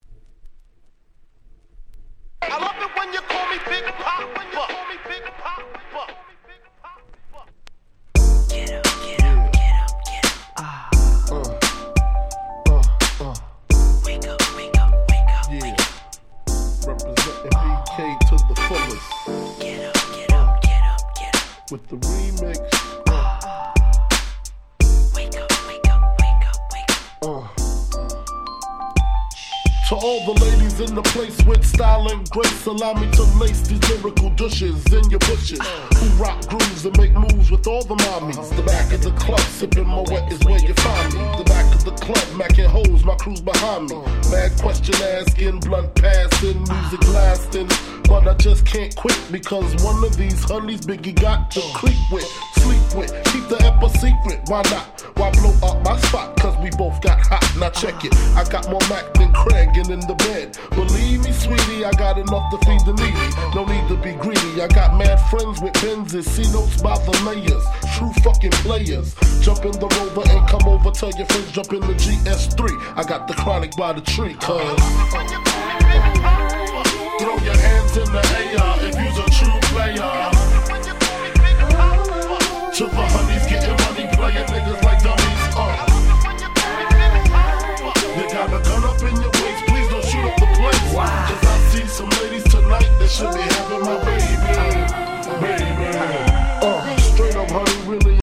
95' Super Hit Hip Hop !!
90's Boom Bap